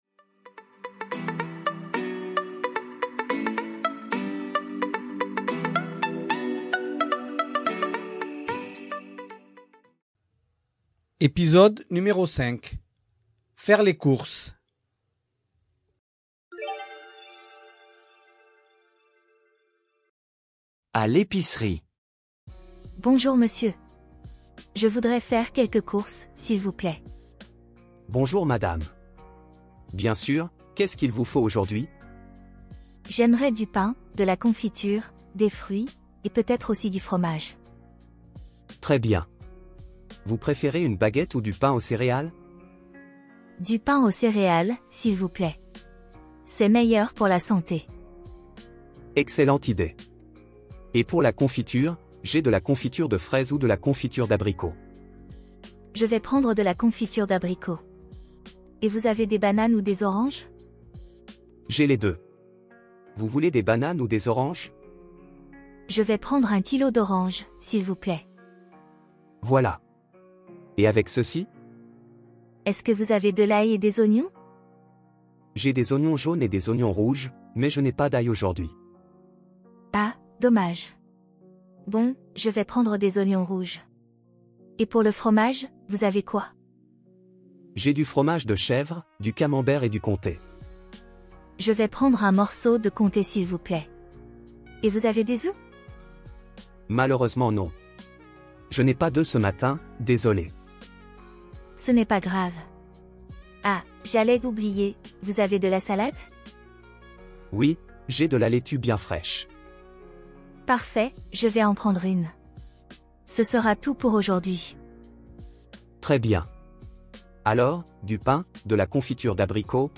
Voici un petit dialogue pour les débutants. Avec cet épisode, vous allez apprendre quelques expressions pour ller faire les courses.